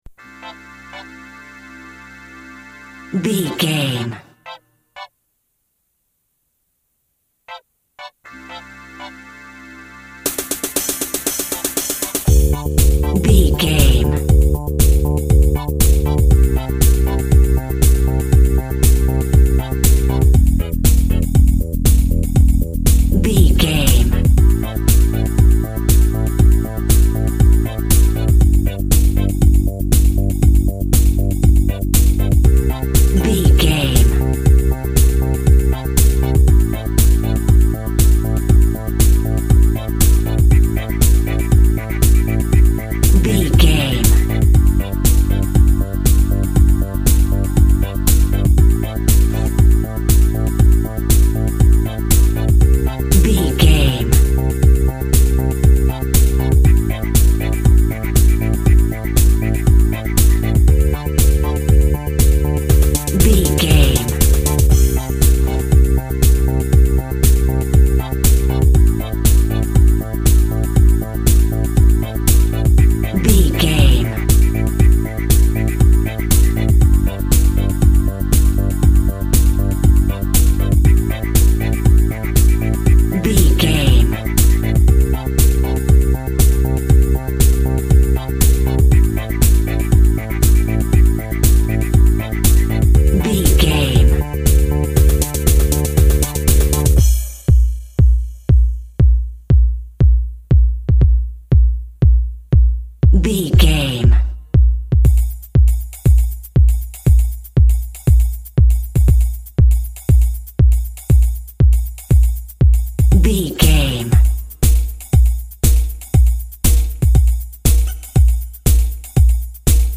Electric Square Waveform.
Aeolian/Minor
groovy
happy
futuristic
uplifting
strings
synthesiser
drums
drum machine
techno
trance
synth lead
synth bass
Synth Pads